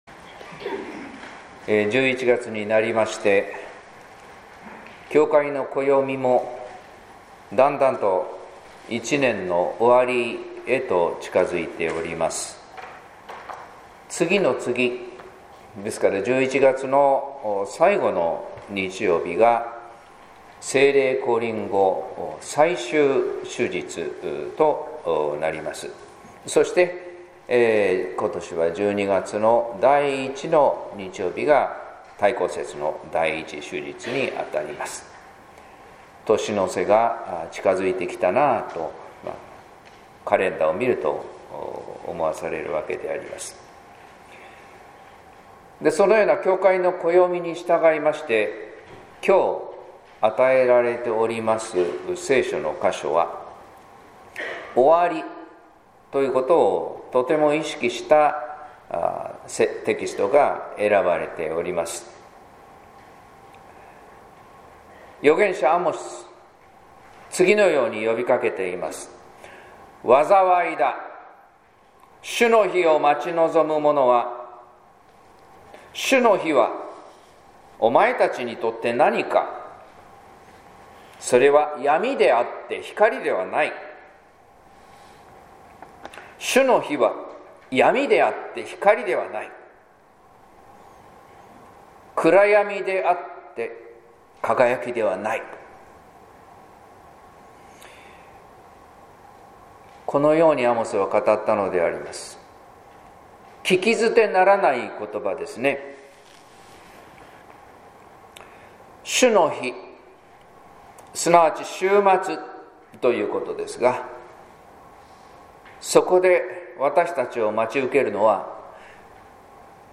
説教「花婿を迎える備え」（音声版）